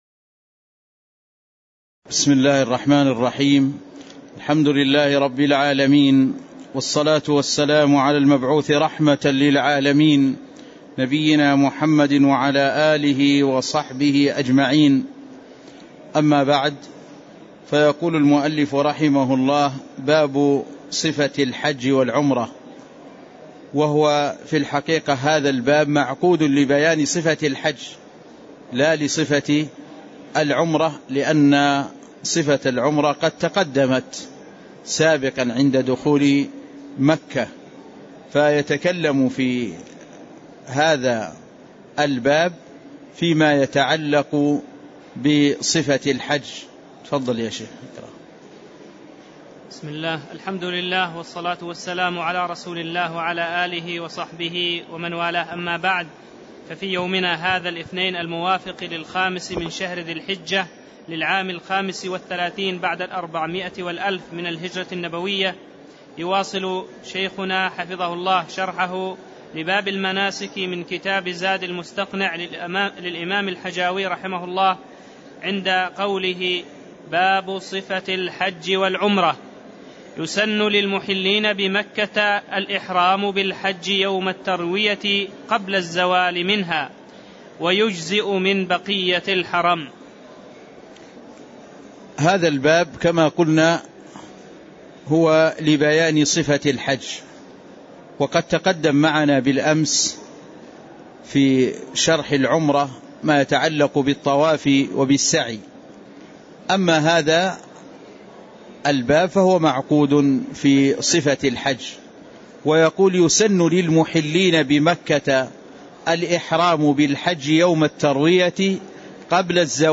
تاريخ النشر ٥ ذو الحجة ١٤٣٥ هـ المكان: المسجد النبوي الشيخ